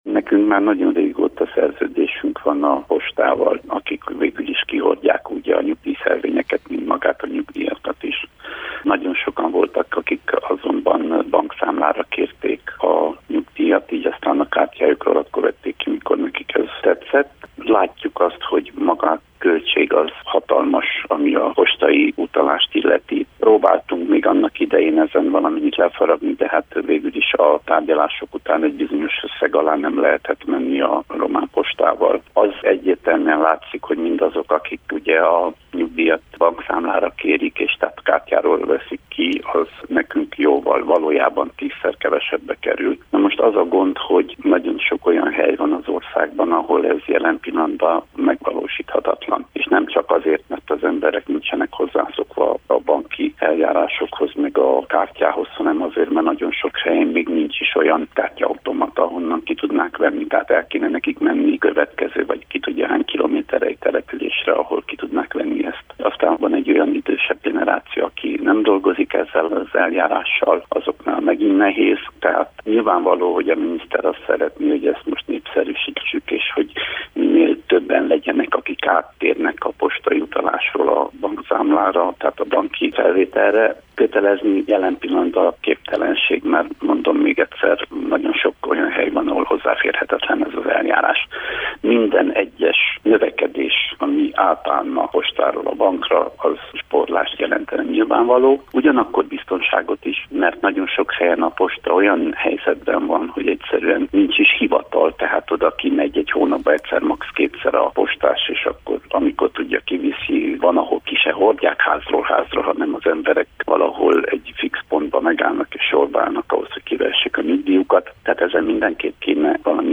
A Kolozsvári Rádiónak nyilatkozva Derzsi Ákos munkaügyi államtitkár elmondta, hogy a népszerűsítő kampány célközönsége a nagyobb és fejlettebb települések nyugdíjas lakossága, mert a vidéken élők számára sok esetben nem áll rendelkezésre bankautomata.